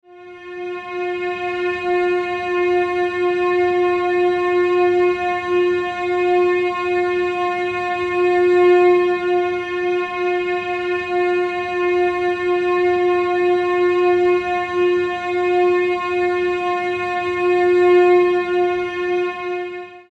Music tones for String Animation Below
23_Medium_F.mp3